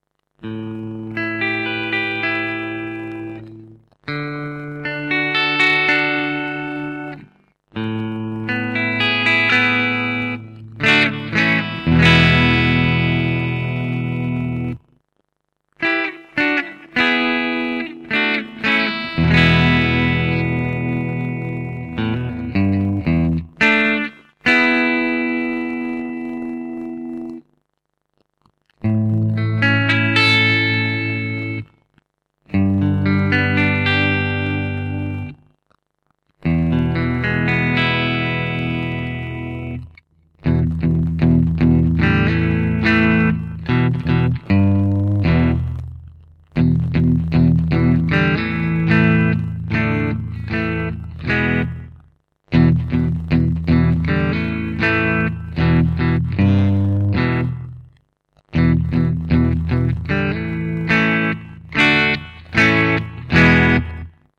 Delta Express vintage alnico 4 humbucker, vintage tone with extra bite
The treble and bass are is big and rounded, the mids are scooped with the emphasis on the lower registers. These pickups don't compress or saturate as fast as other PAF types. They do remain musical over a wide gain range. The neck pickup is warm but clear while the bridge has hotter unbalanced coils to give you the extra drive needed and a touch more dirt.
Listen here:     Bridge Full